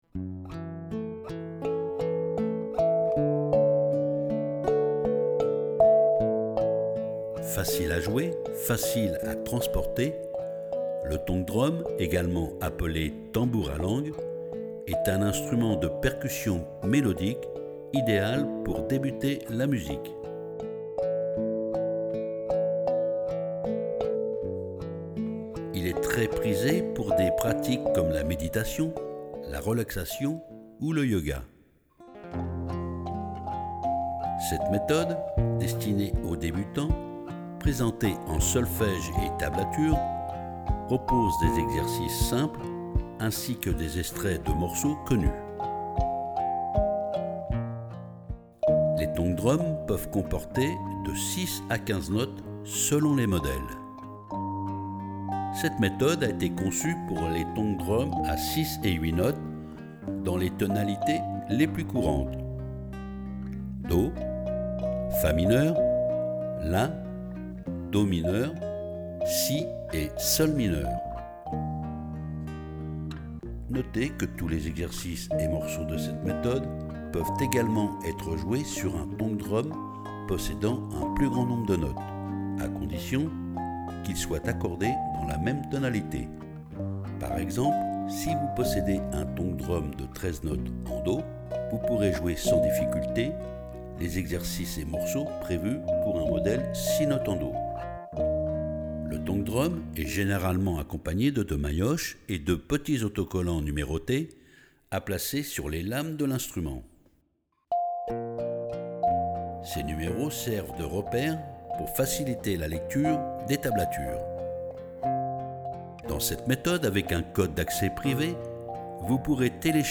Zen et mélodique !